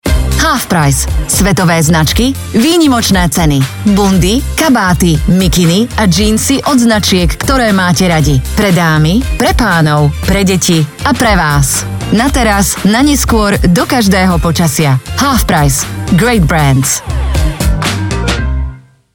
Native speaker Female 20-30 lat
Native Slovak voice artist with a young, fresh female voice.
Instore add